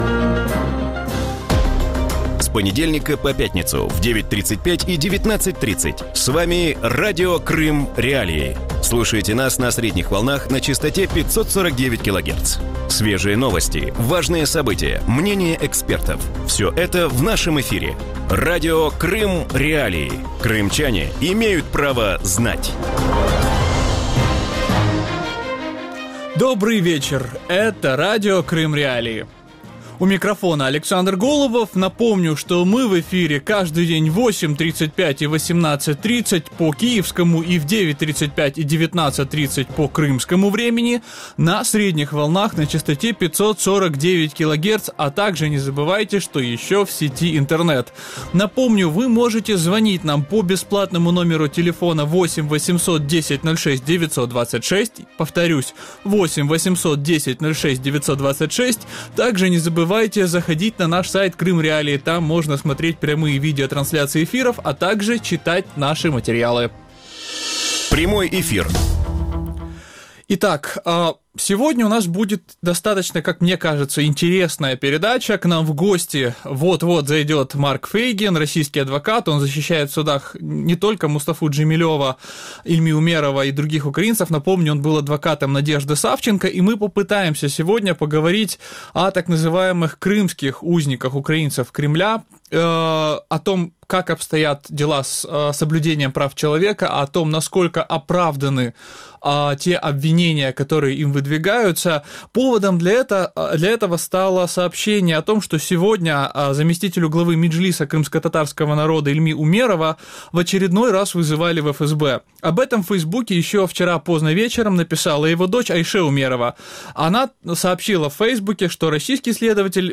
Крымские узники Кремля. Интервью с Марком Фейгиным
В вечернем эфире Радио Крым.Реалии говорят о российской системе правосудия и украинских гражданах, осужденных в России и на полуострове. Как российский репрессивный аппарат обходится с украинскими политзаключенными и можно ли победить судебную систему России? На эти вопросы ответит российский адвокат Марк Фейгин.